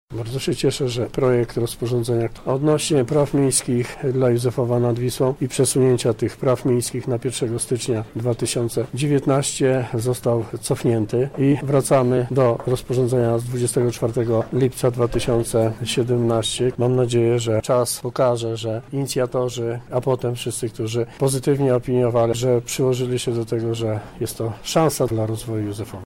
O decyzji mówi wójt gminy Józefów, Grzegorz Kapica